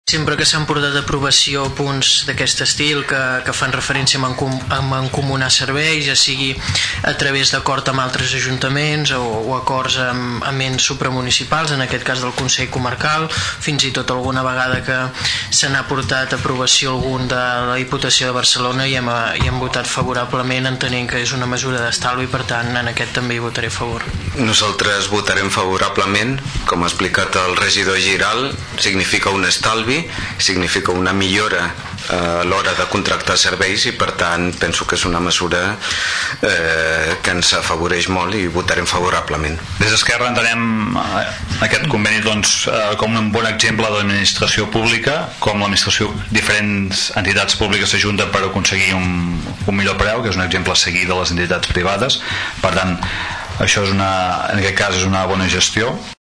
Els partits polítics a l’oposició van mostrar-se favorables a l’adhesió. Escoltem Xavier Martin del PP, Rafa Delgado del PSC i Xavi Pla d’ ERC, respectivament.